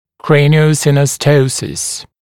[ˌkreɪnɪə(u)ˌsɪnɔˈstəusəs][ˌкрэйнио(у)ˌсиноˈстоусэс]краниосиностоз